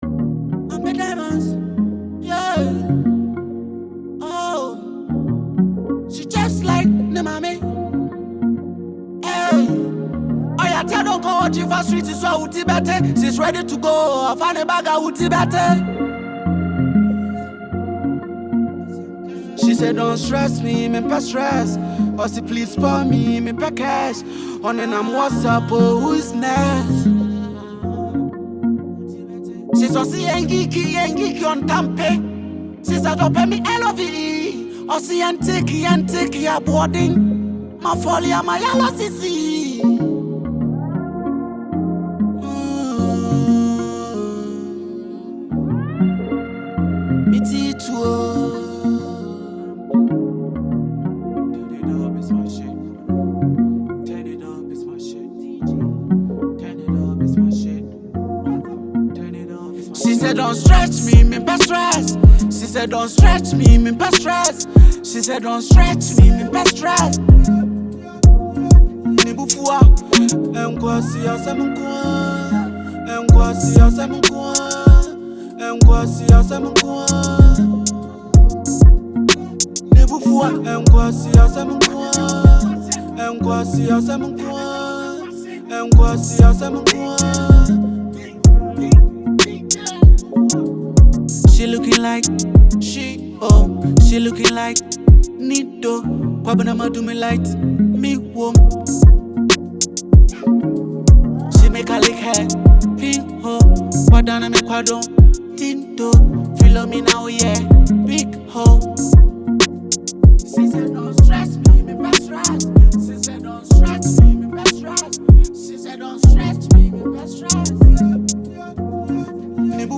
The track is bold, offbeat, and everything fans love